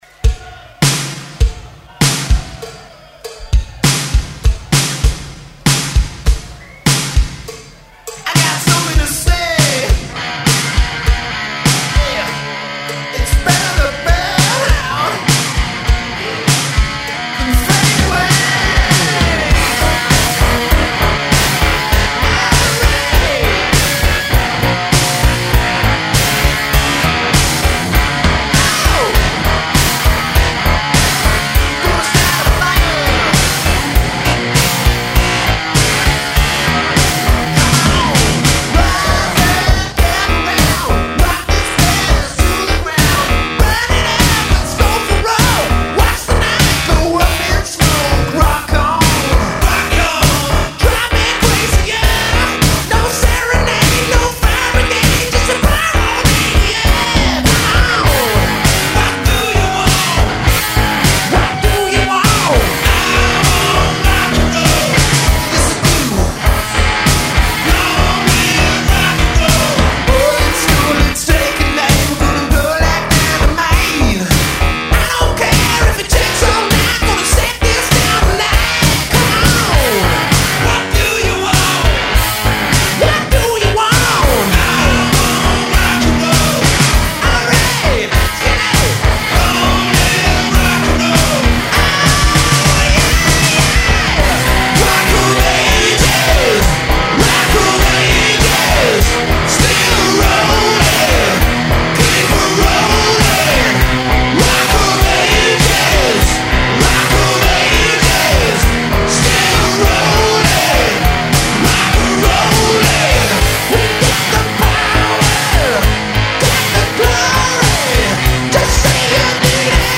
live mashup